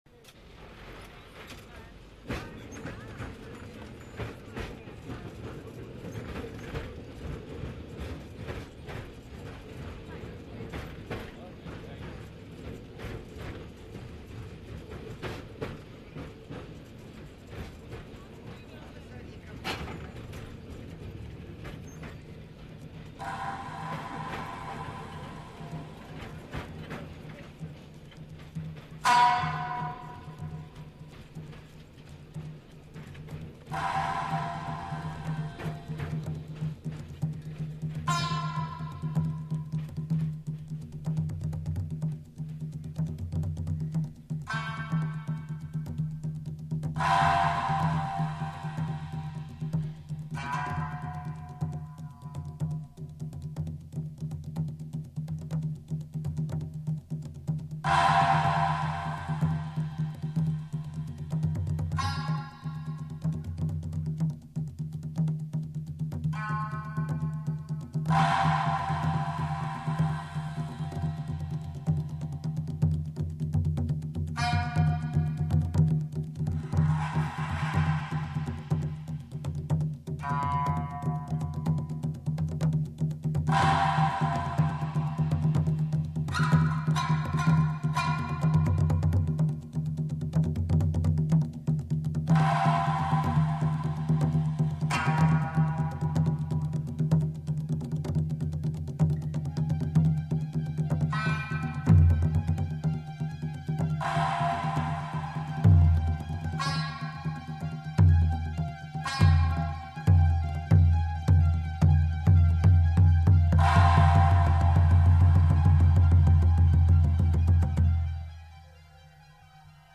幽昧深遠，神秘而浪漫